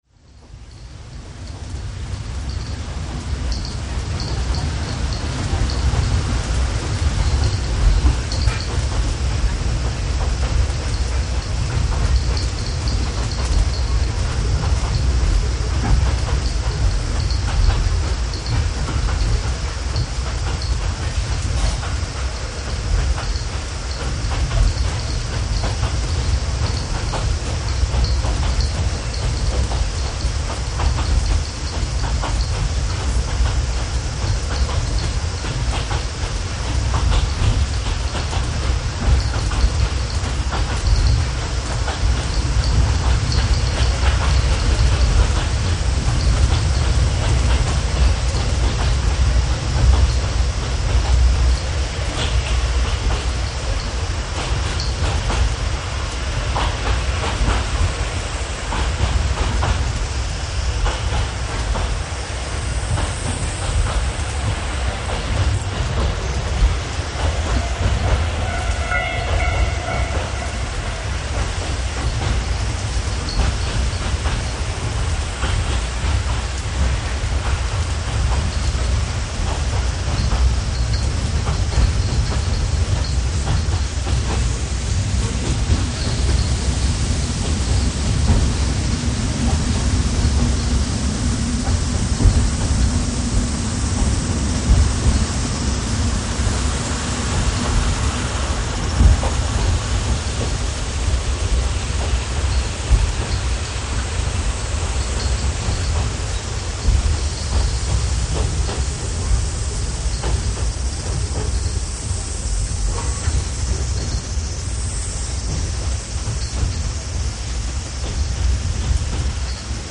真夏の若桜線　ＤＥ１０が牽く鳥取行き３２２列車　昭和56年８月
せみ時雨の中をとろとろ走っています。